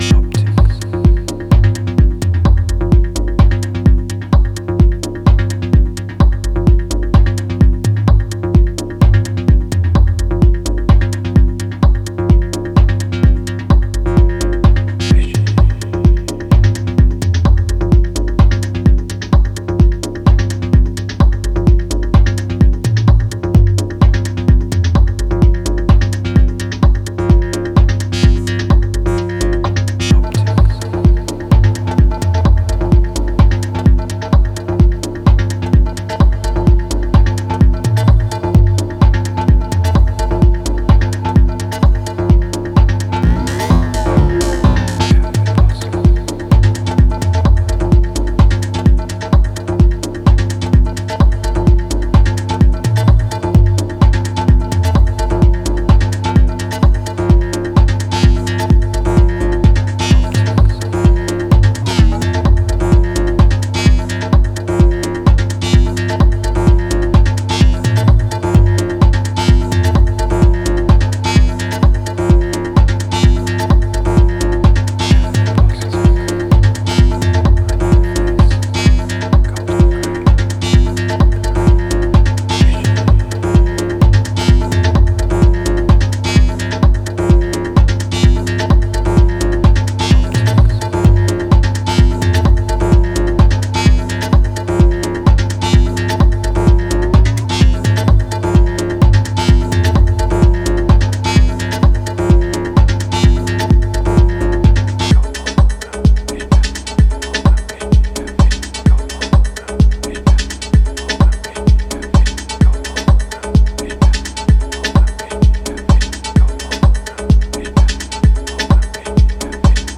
House Techno Breaks